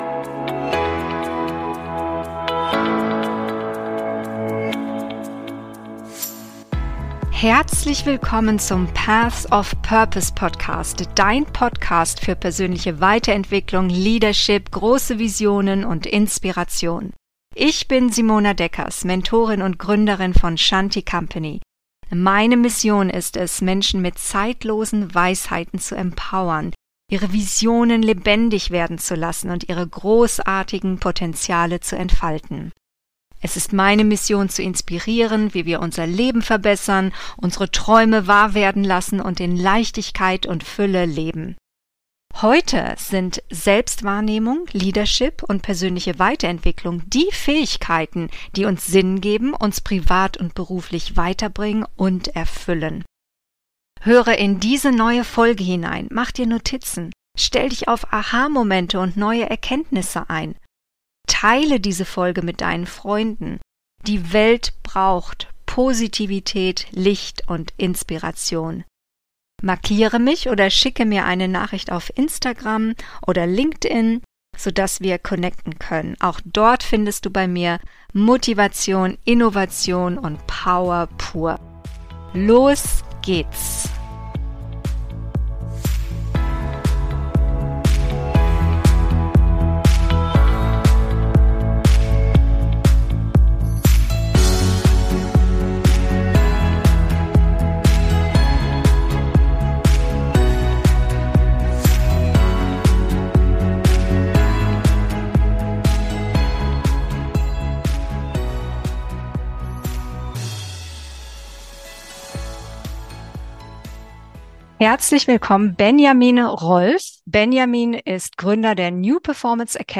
Neues Arbeiten: gesund und erfolgreich - Interview